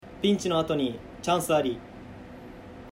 選手ボイス